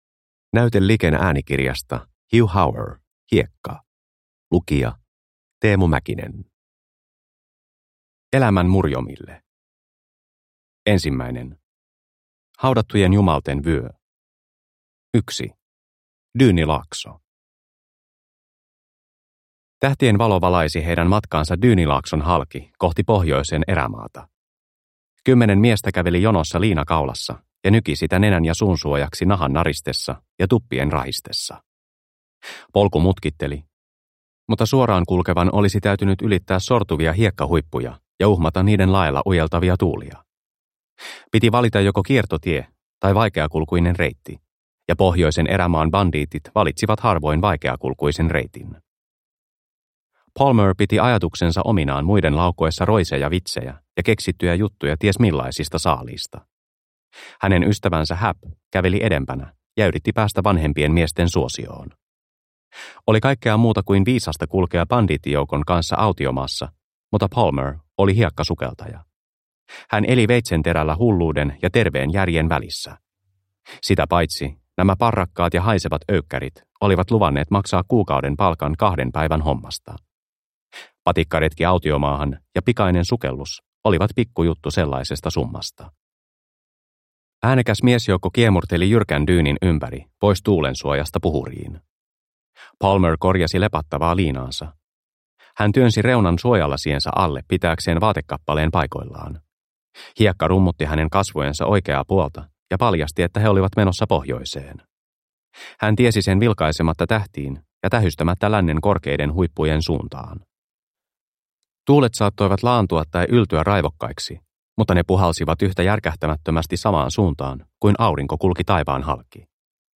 Hiekka – Ljudbok – Laddas ner